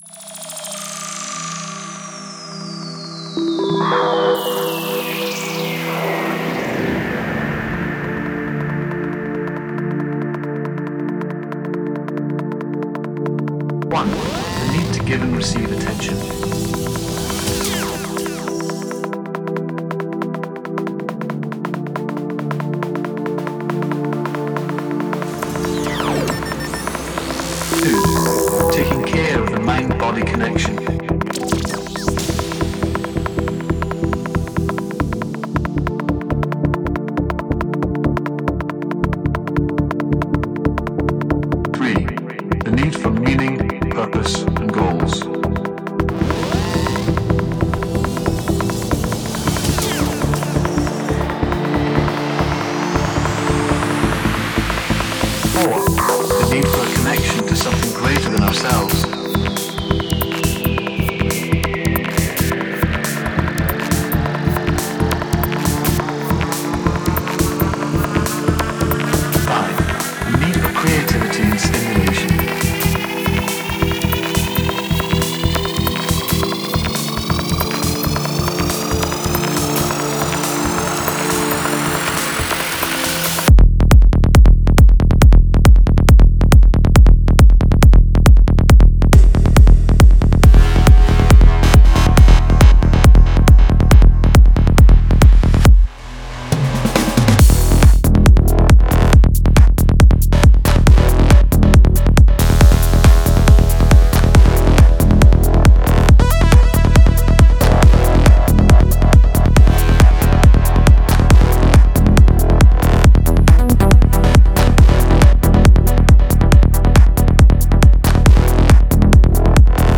Файл в обменнике2 Myзыкa->Psy-trance, Full-on
Стиль: Trance / Psy Trance